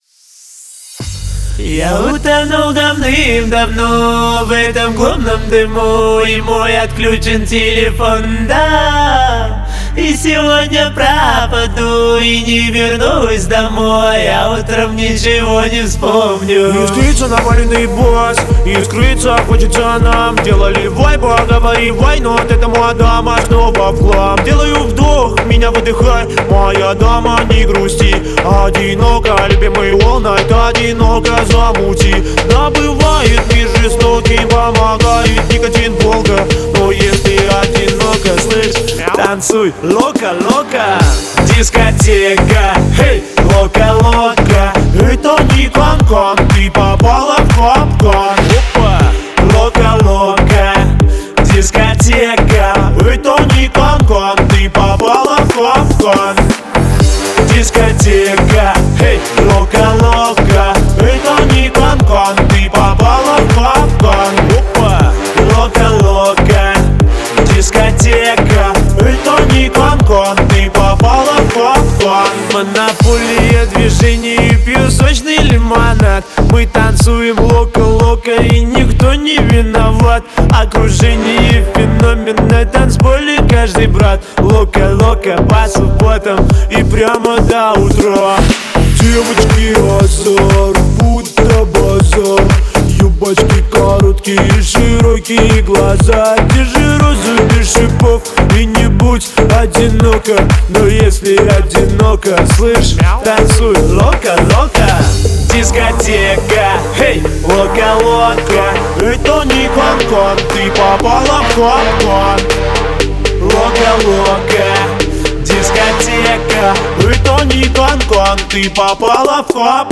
энергичная танцевальная композиция